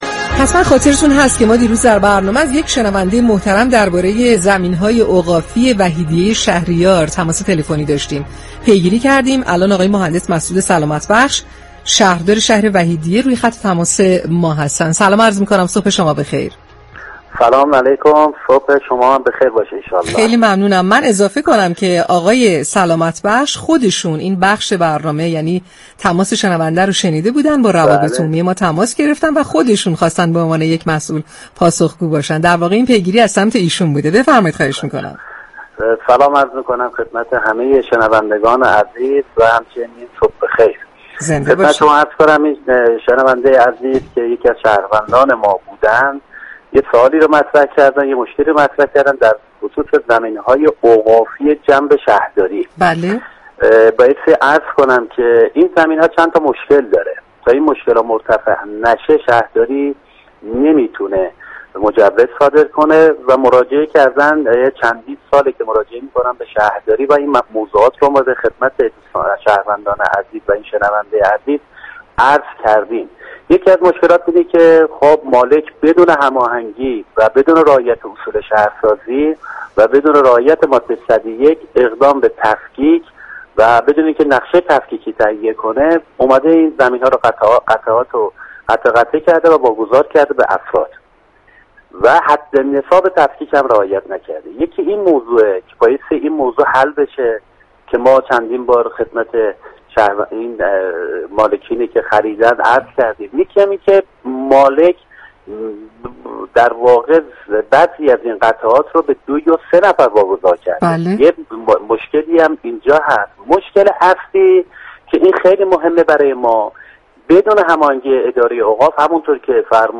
به گزارش پایگاه اطلاع رسانی رادیو تهران؛ مسعود سلامت بخش شهردار وحیدیه شهریار در گفت و گو با "شهر آفتاب" رادیو تهران درخصوص عدم صدور مجوز ساخت از سوی شهرداری برای صاحبان زمین‌های اوقافی وحیدیه شهریار گفت: متاسفانه زمین‌های اوقافی جنب شهرداری وحیدیه شهریار مشكلات زیادی دارد و تا زمانی كه این مشكلات حل نشود شهرداری نمی‌تواند برای صاحبان این زمین‌ها مجوز ساخت صادر كند.